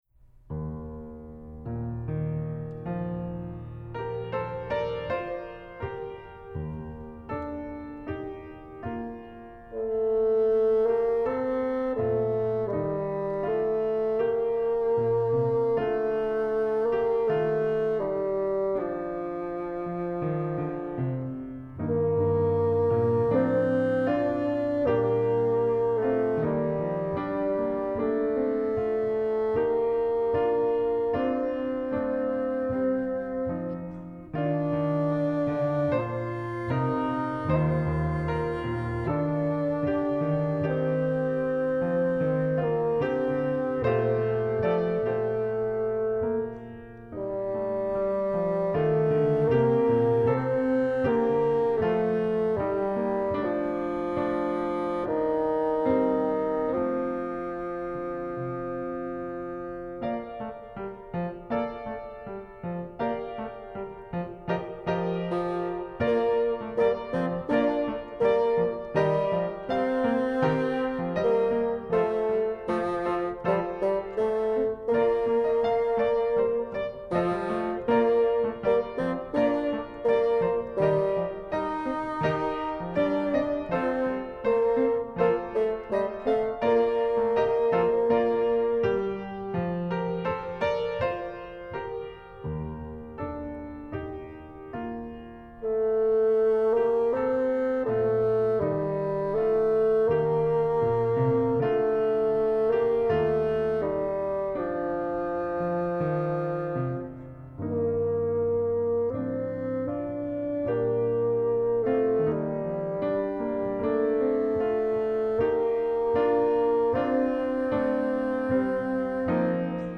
Style: Lyrical/Technical
Bassoon and Piano